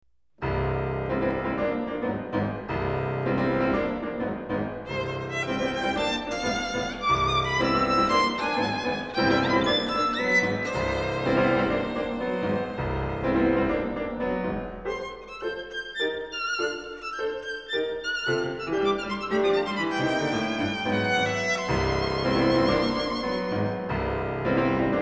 03] violin, piano